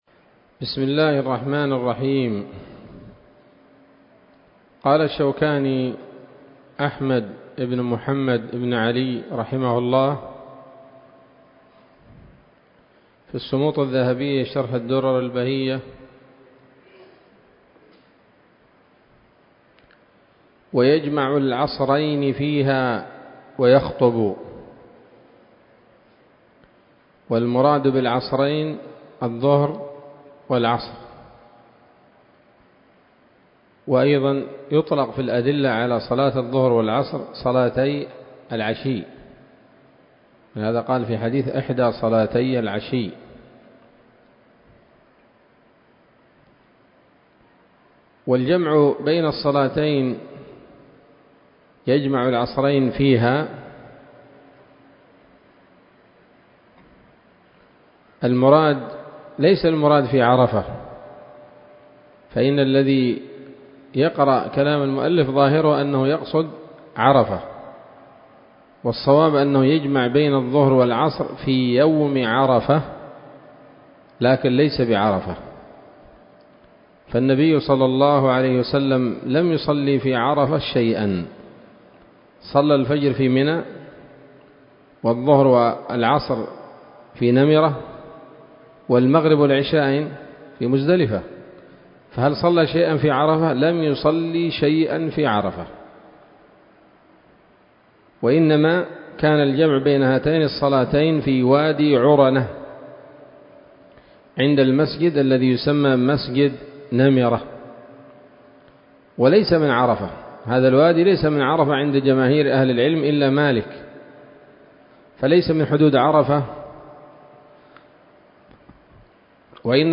الدرس التاسع عشر من كتاب الحج من السموط الذهبية الحاوية للدرر البهية